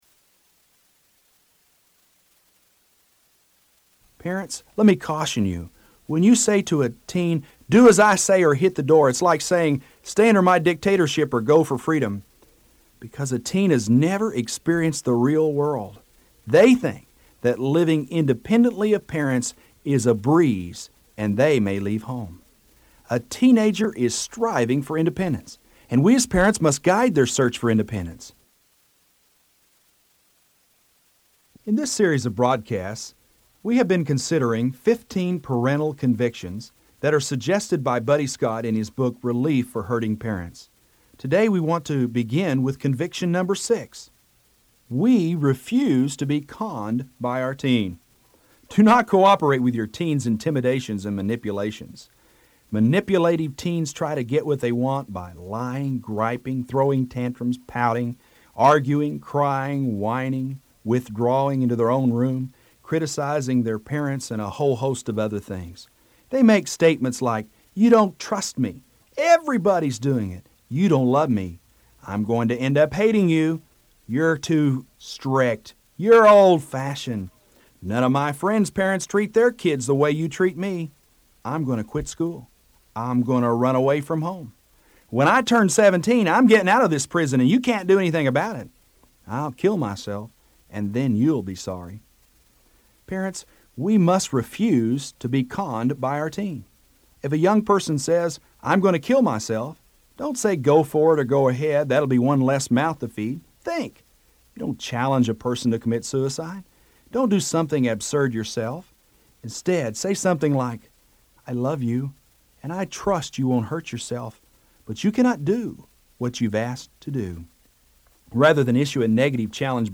Sermon #6